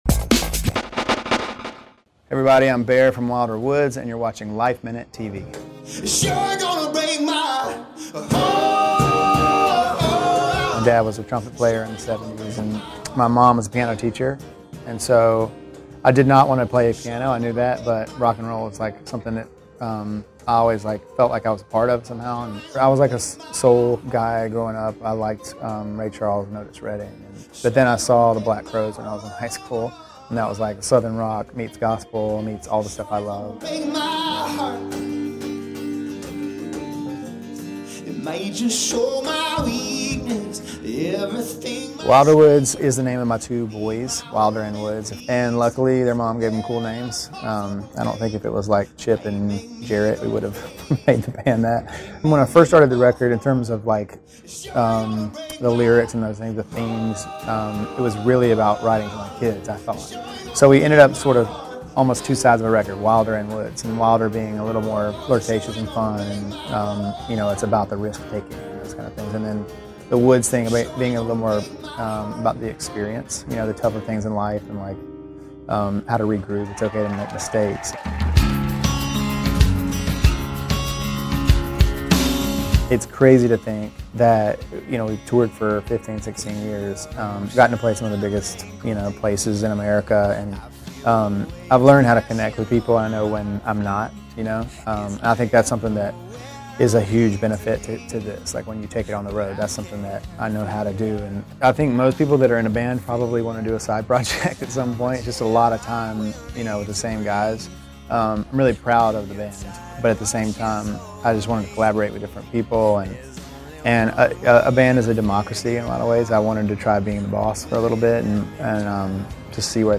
We got a taste of the new groove during an acoustic performance at Atlantic Records in New York City.